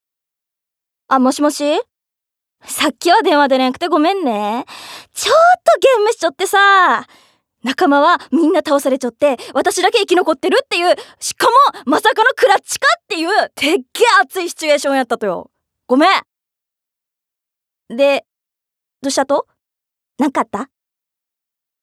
ボイスサンプル
セリフ７